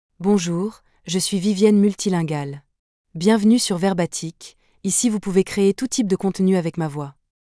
Vivienne Multilingual — Female French (France) AI Voice | TTS, Voice Cloning & Video | Verbatik AI
Vivienne Multilingual is a female AI voice for French (France).
Voice: Vivienne MultilingualGender: FemaleLanguage: French (France)ID: vivienne-multilingual-fr-fr
Voice sample
Listen to Vivienne Multilingual's female French voice.
Vivienne Multilingual delivers clear pronunciation with authentic France French intonation, making your content sound professionally produced.